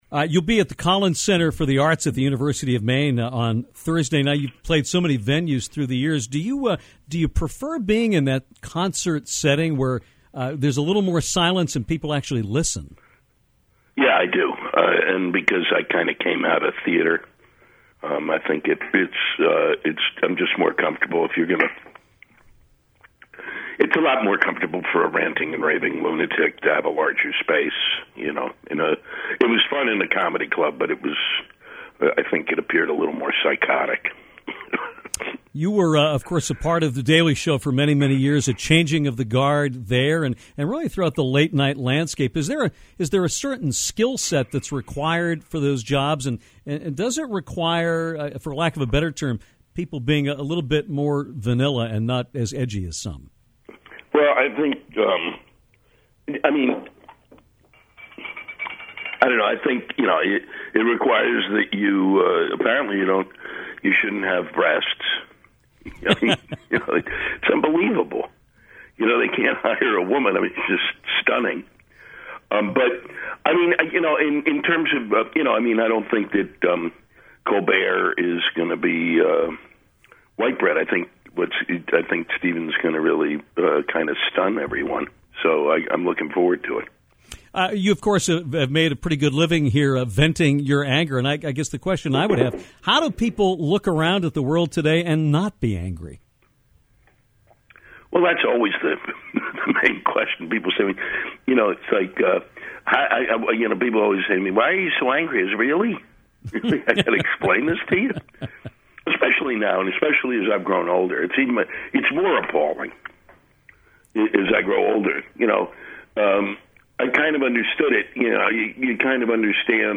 Comedian Lewis Black came on Downtown to talk about his upcoming show at the Collins Center for the Arts at the University of Maine on Thursday. Black discussed his roots in theater, his work on The Daily Show, his new Pixar film “Inside Out”, and his love of the work of author Kurt Vonnegut.